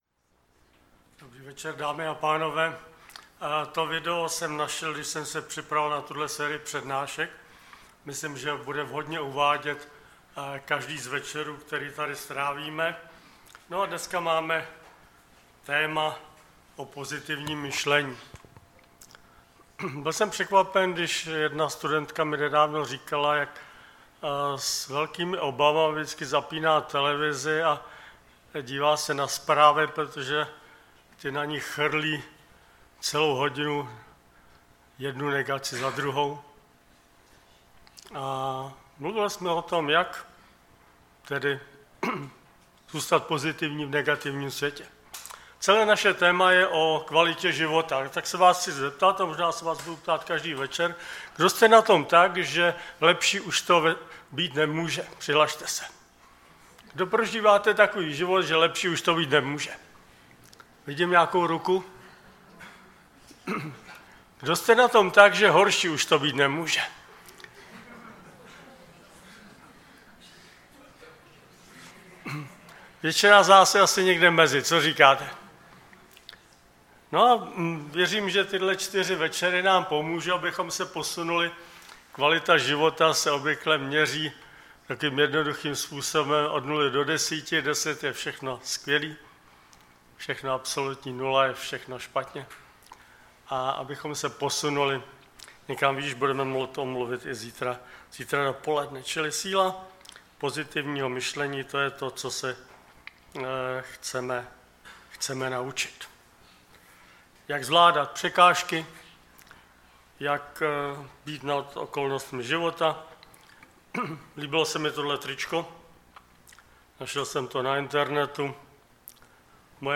Série: Co rozhoduje o kvalitě života? Typ Služby: Přednáška Preacher